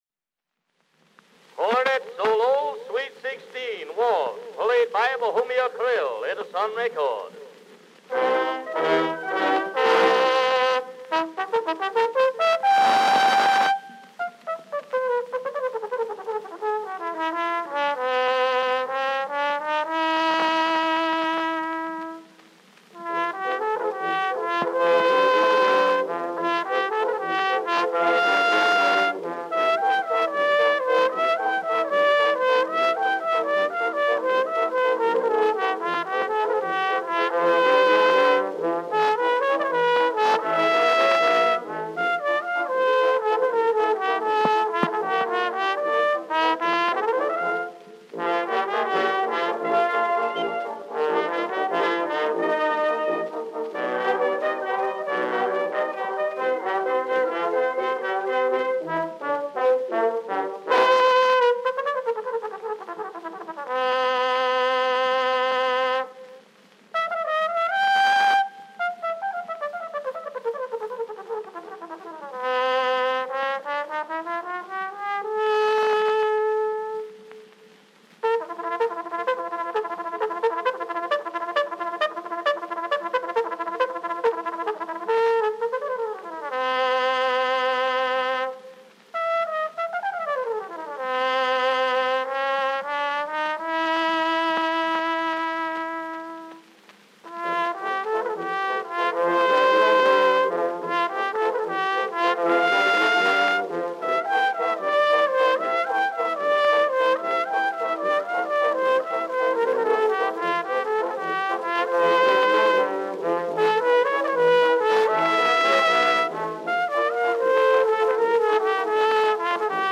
Cornet solo with orchestra accompaniment.
Popular instrumental music—1901-1910.
Cornet with orchestra.